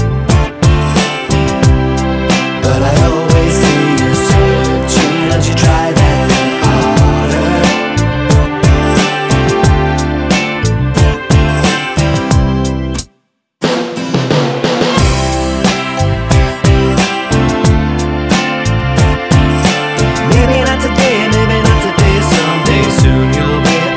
Male Solo Version Pop (2000s) 3:32 Buy £1.50